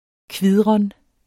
Udtale [ ˈkviðʁən ]